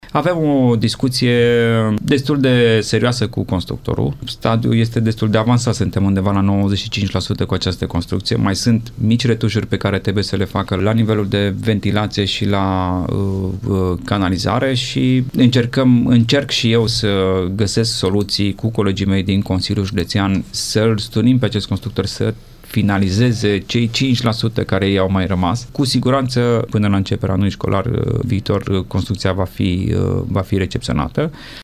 Lucrările la noua clădire de pe strada Cornelia Sălceanu sunt finalizate în proporție de 95%, însă există unele întârzieri înregistrate de constructor, spune Alexandru Iovescu, vicepreședintele Consiliului Județean Timiș.
Alexandru-Iovescu-Speranta.mp3